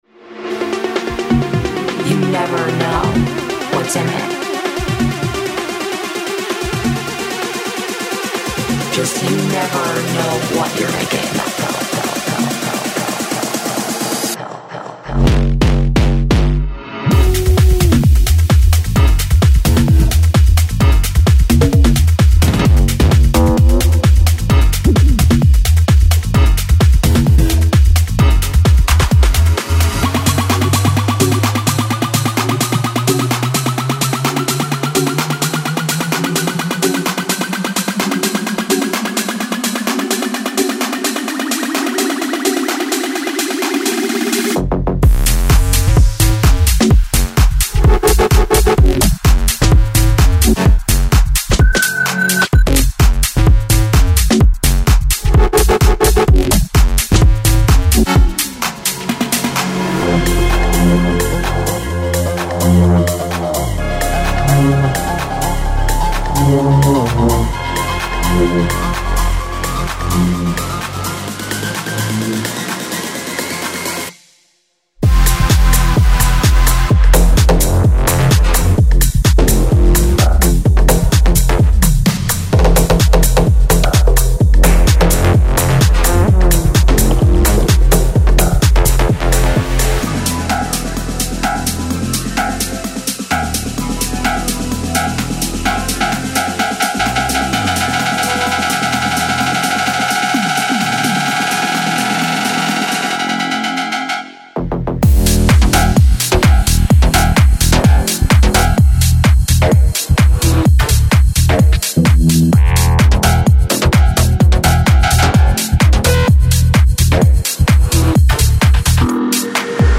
Genre:Tech House
デモサウンドはコチラ↓
27 Bass Loops
1 Saxophone
25 Synth Loops